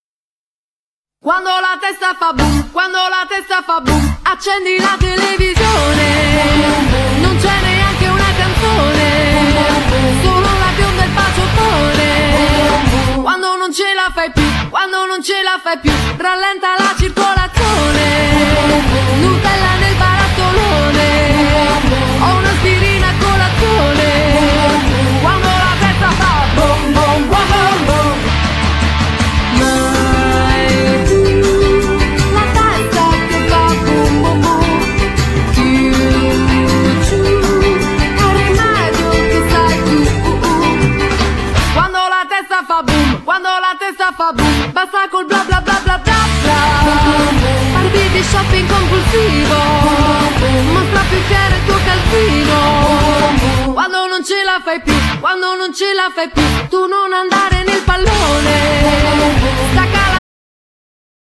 Genere : Metal